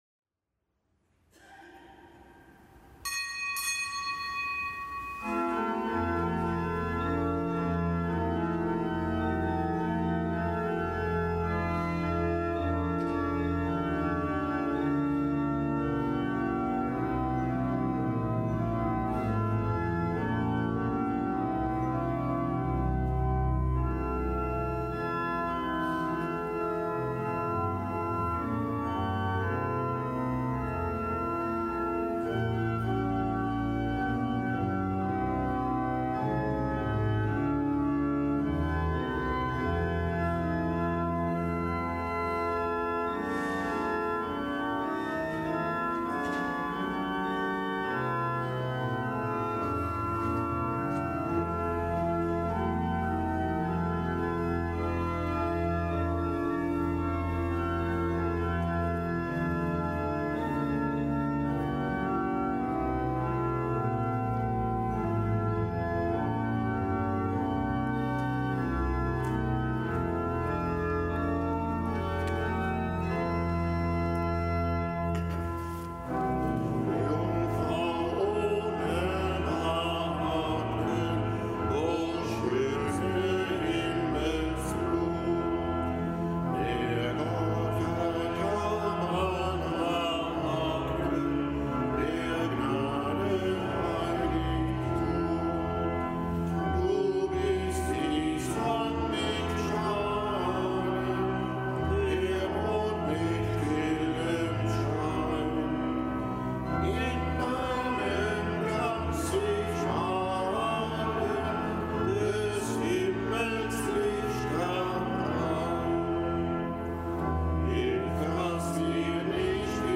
Kapitelsmesse aus dem Kölner Dom am Dienstag der fünften Woche im Jahreskreis, nichtgebotener Gedenktag Unserer Lieben Frau in Lourdes. Zelebrant: Weihbischof Dominikus Schwaderlapp